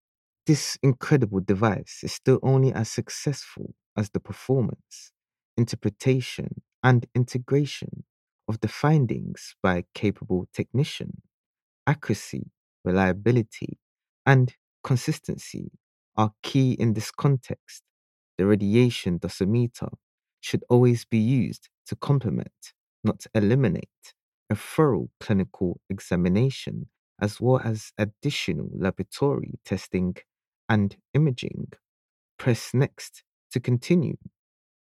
Explainer & Whiteboard Video Voice Overs
Yng Adult (18-29) | Adult (30-50)